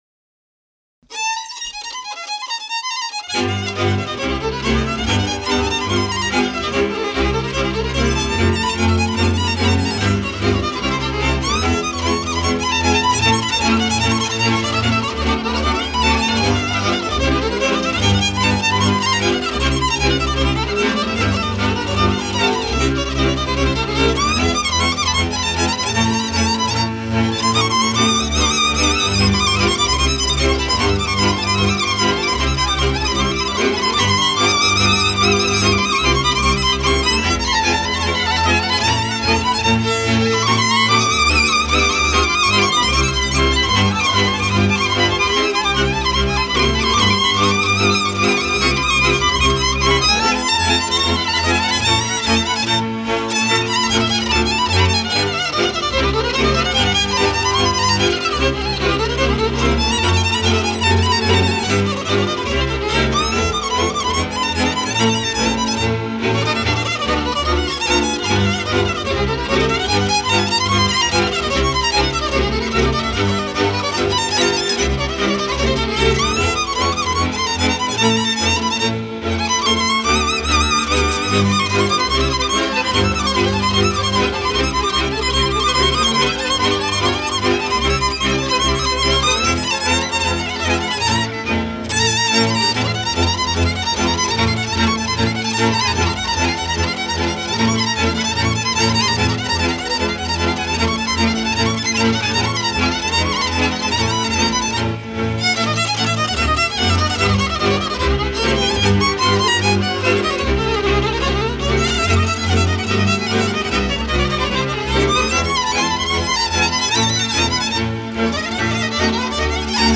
hegedű
kontra
bőgő